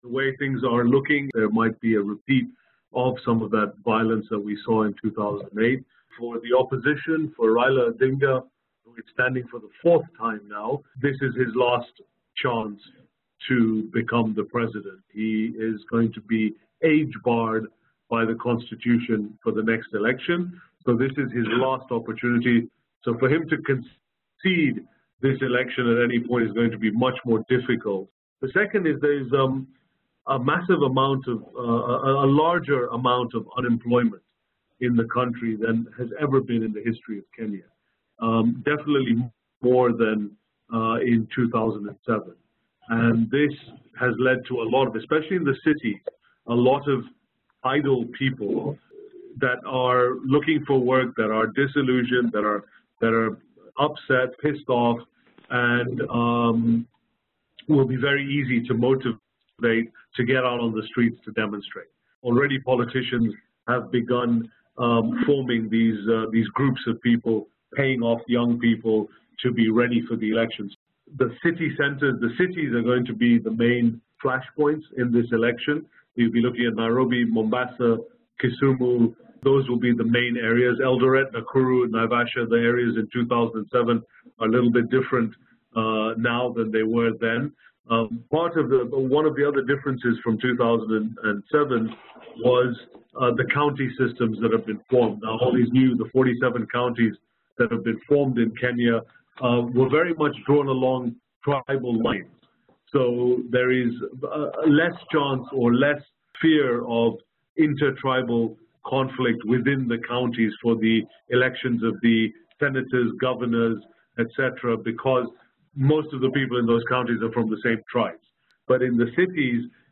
Webinar: Covering the Kenyan Elections